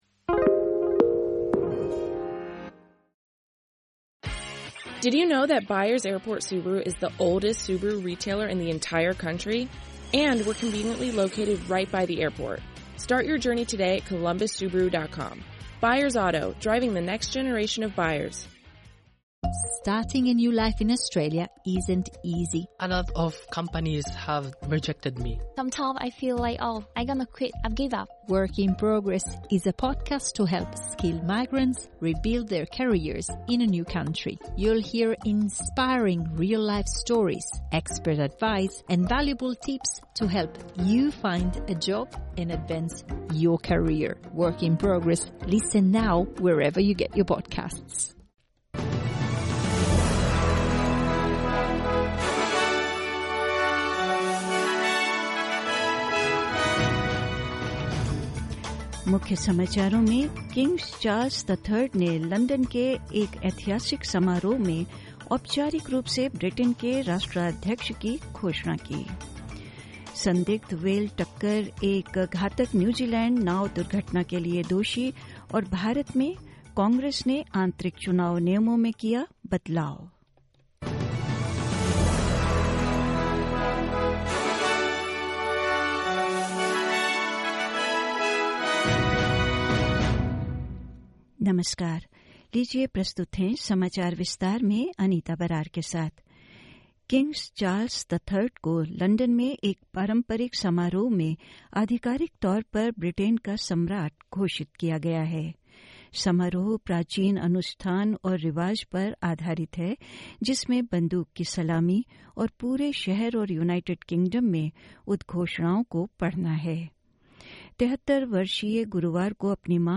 In this Hindi bulletin: King Charles the Third formally proclaimed Britain's head of state at a historic London ceremony; Suspected whale collision blamed for a fatal New Zealand boat accident and more news'